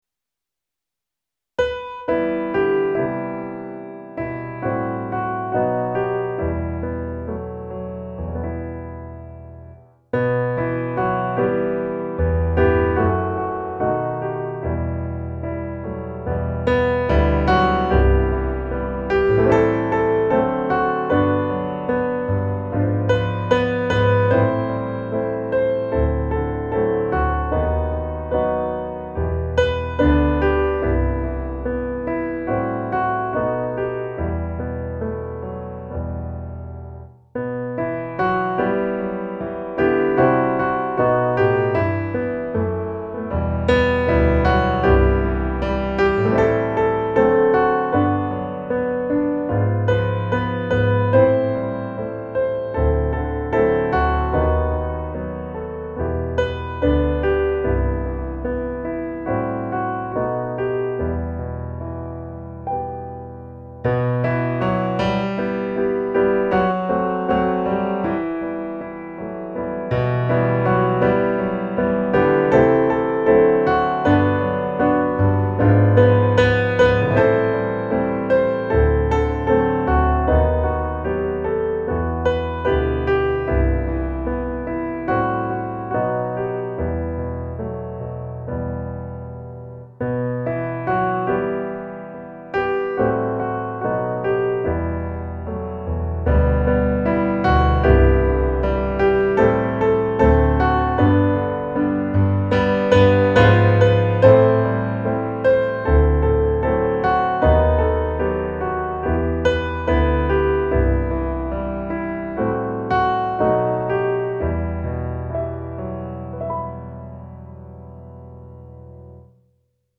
musikbakgrund
Gemensam sång
Musikbakgrund Psalm